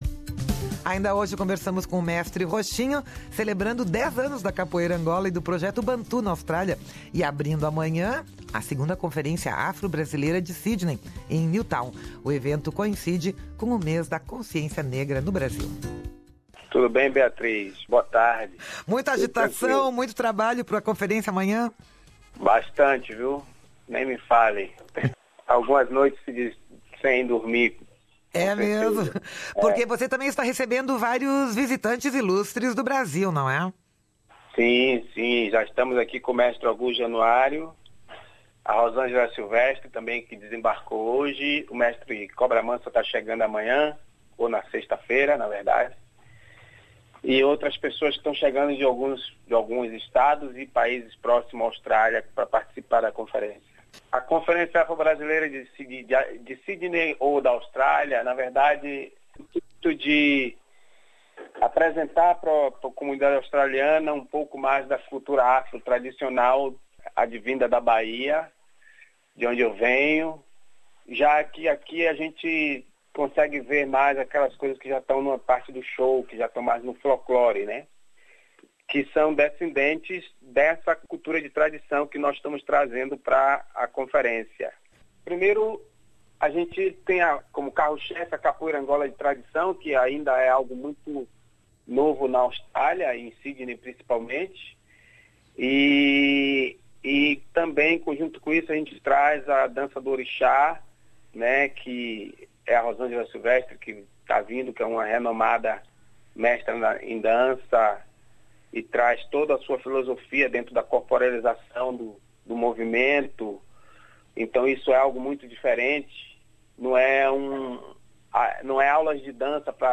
fala nesta entrevista do brilho nos olhos e do sorriso nos lábios de jovens marginalizados